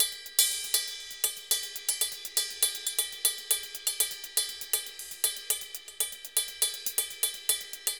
Ride_Candombe 120_1.wav